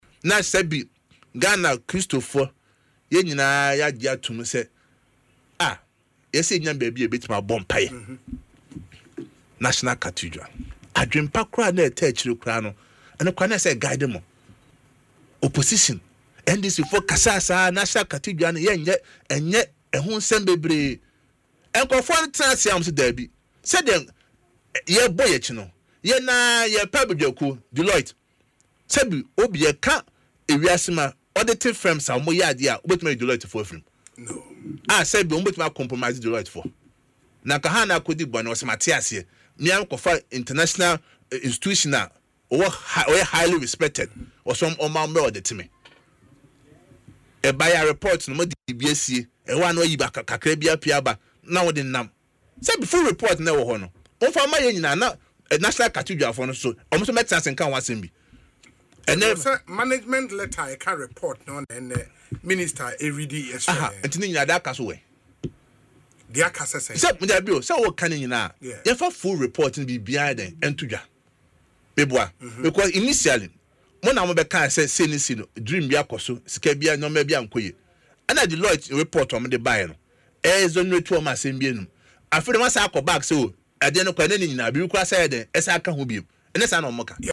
Speaking on Asempa FM’s Ekosii Sen